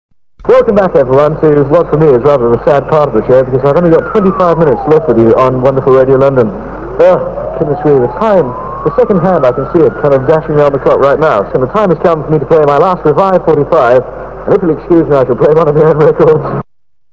click to hear audio a short clip of Tony on his last ever programme on Big L, the Breakfast Show, 15th July 1967 (duration 17 seconds)